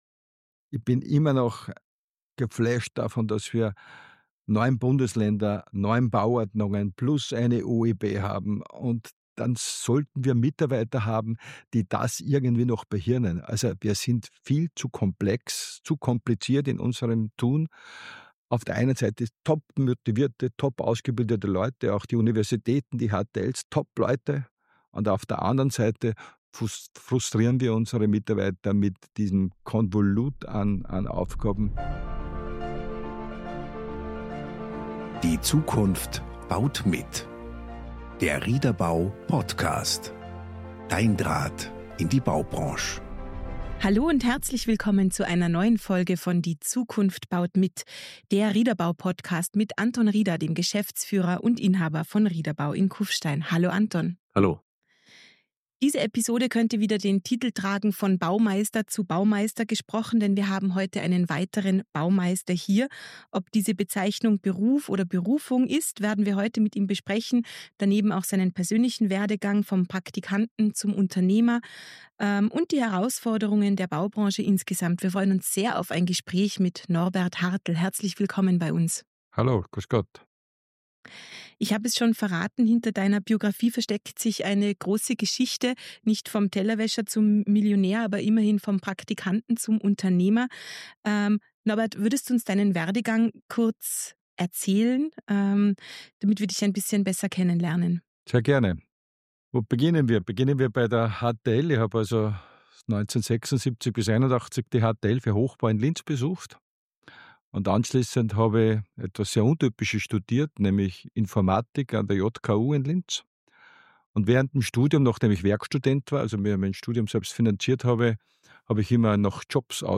Von Baumeister zu Baumeister – ein Gespräch über Unternehmertum, Verantwortung und die Zukunft des Bauens.